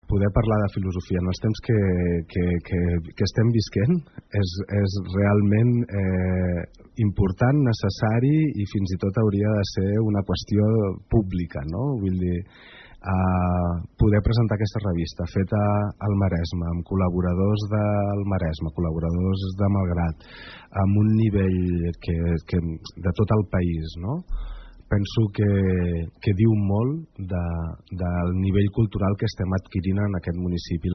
N’ha parlat el regidor de Cultura de l’Ajuntament de Malgrat de Mar, Paco Márquez, en declaracions al programa Ona Maresme.